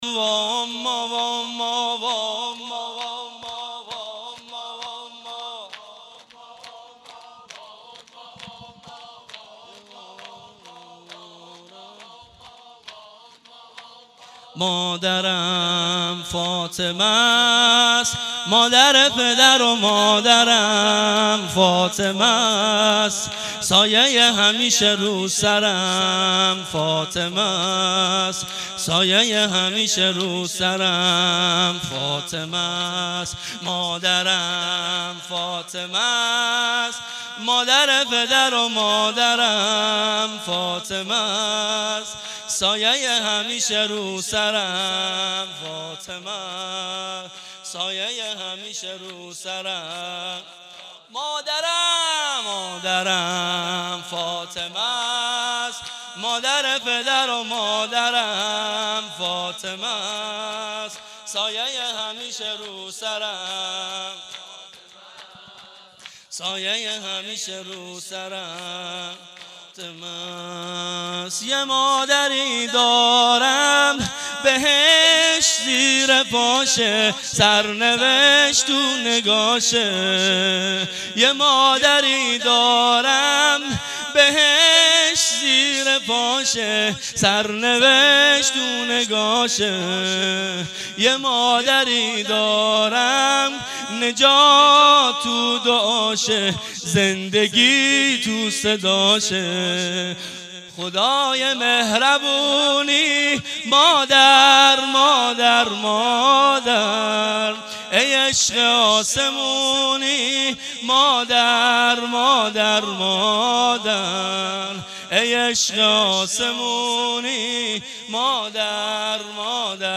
فاطمیه97- مجمع دلسوختگان بقیع- شب دوم- شور